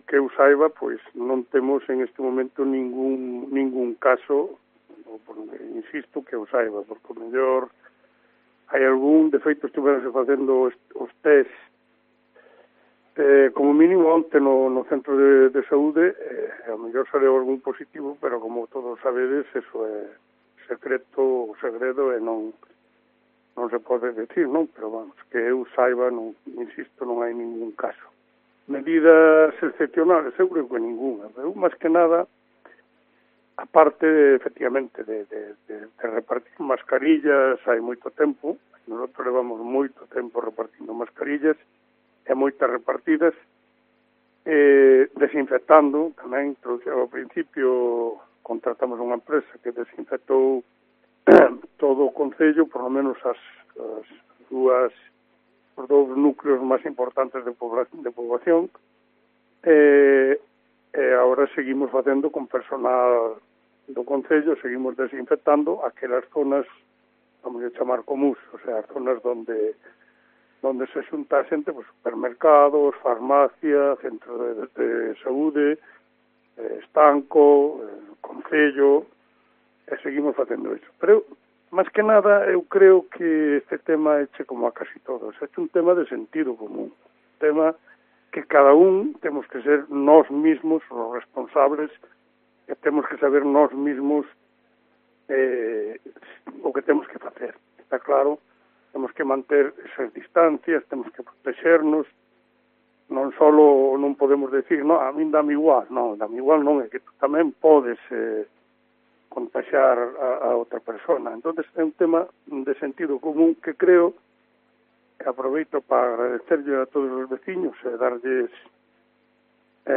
Declaraciones de JESÚS NOVO, alcalde de O Vicedo